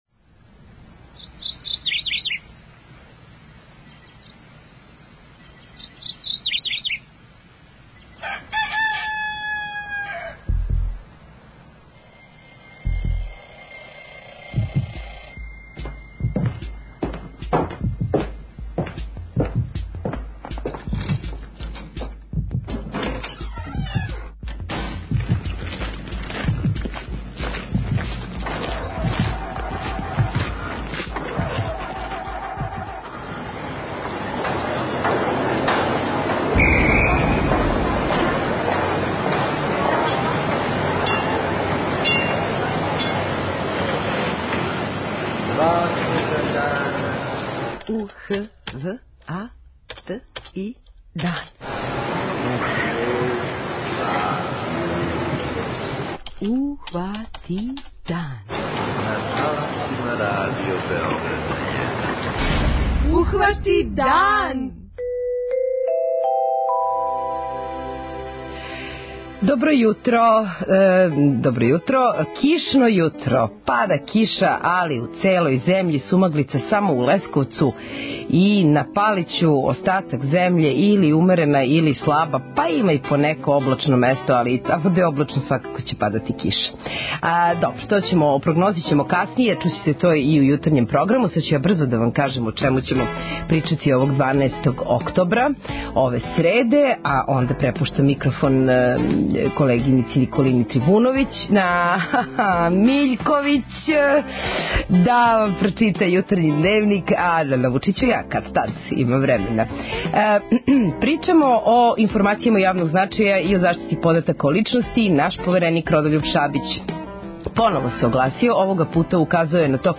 Повереник за информације од јавног значаја и заштиту података о личности Родољуб Шабић указао је на неправилности у коришћењу података који се налазе у бази здравственог фонда. О којим неправилностина је реч и како их уклонити, објасниће господин Шабић у телефонском укључењу.